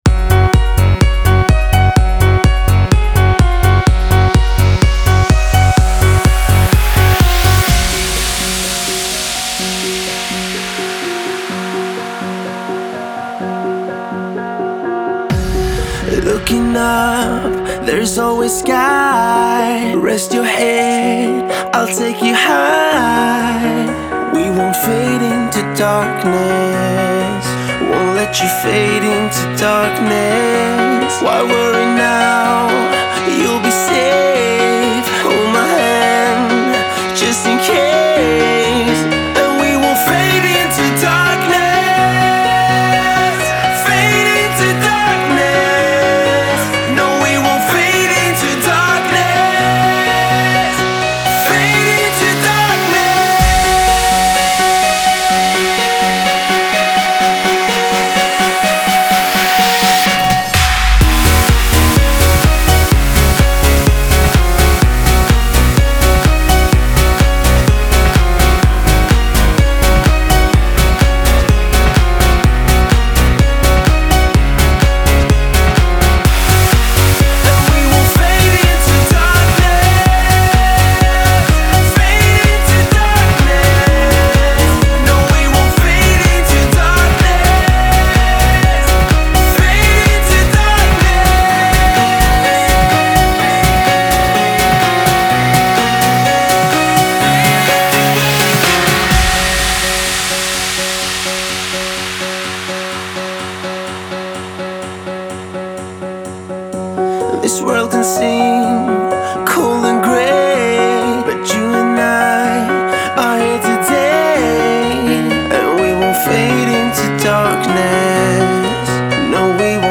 Radio Vocal Mix